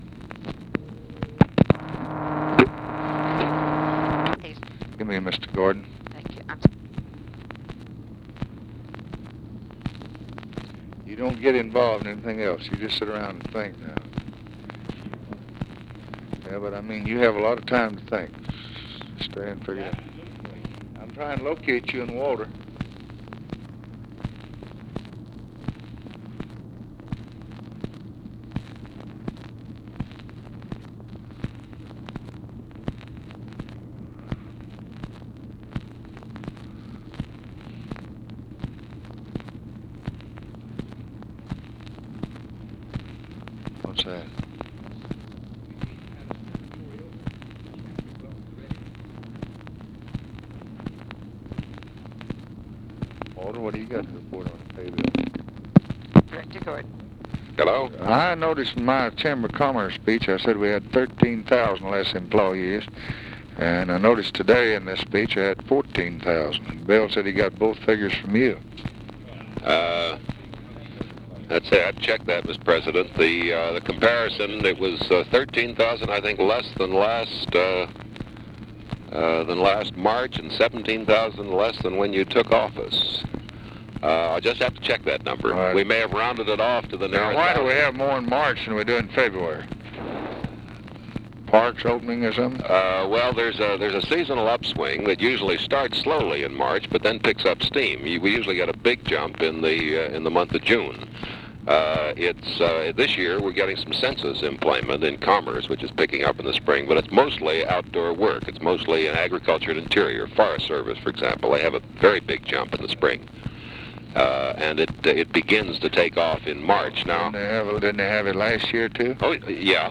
Conversation with KERMIT GORDON and OFFICE CONVERSATION, May 2, 1964
Secret White House Tapes